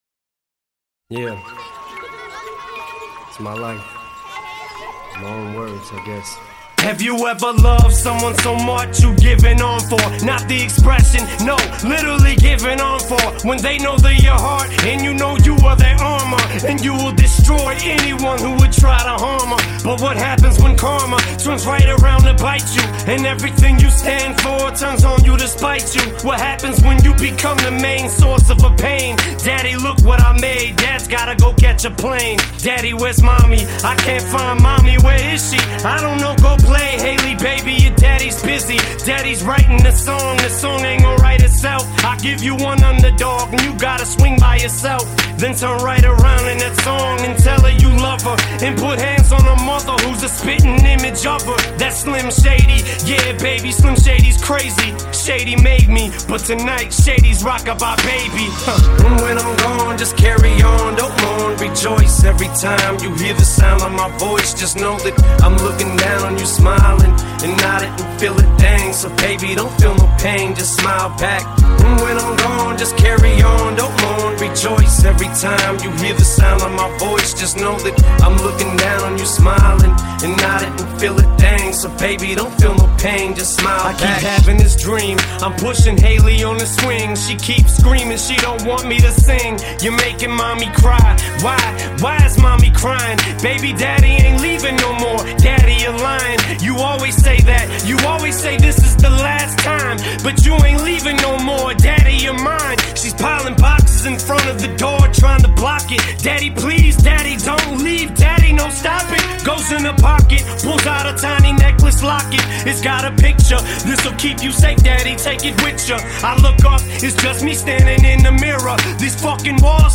Жанр: Hip-Hop